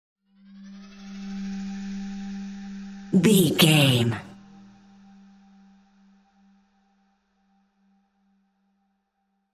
Scary Lo Ripple.
In-crescendo
Aeolian/Minor
scary
tension
ominous
dark
suspense
eerie
Horror Synths
Scary Piano
Scary Strings